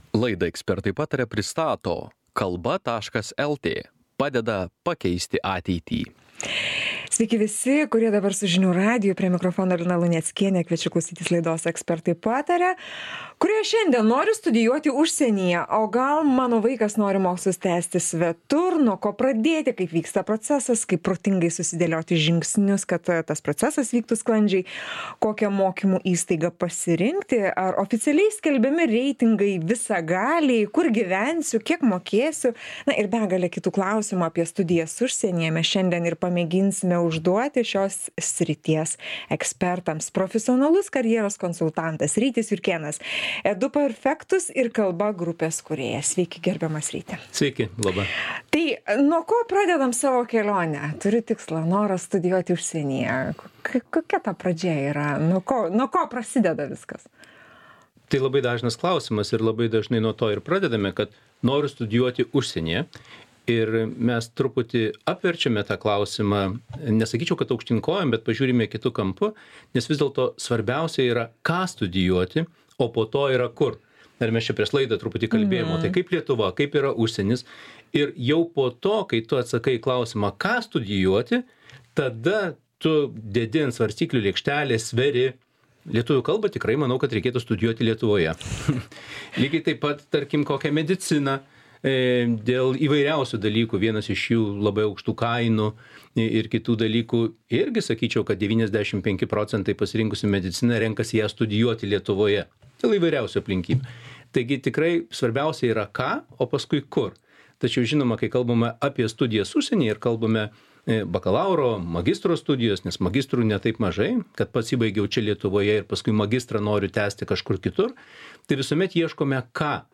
Laidą „Ekspertai pataria" pristato Kalba lt - padeda pakeisti ateitį!